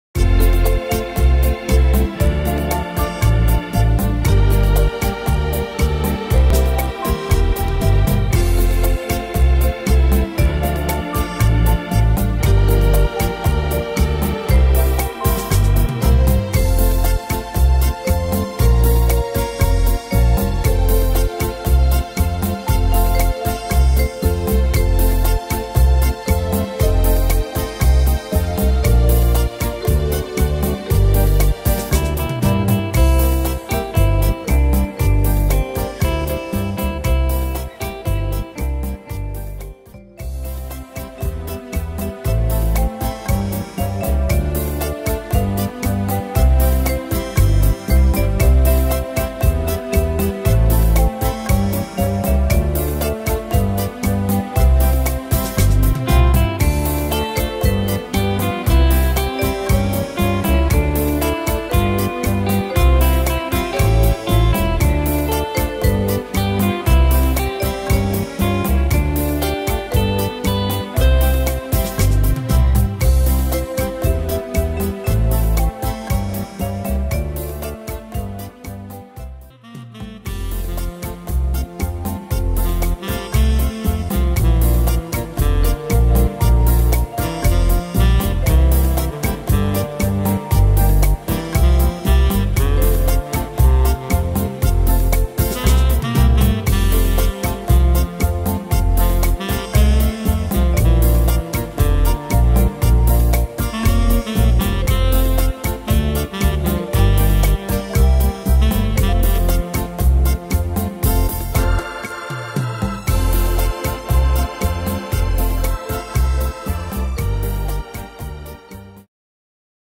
Tempo: 117 / Tonart: F-Dur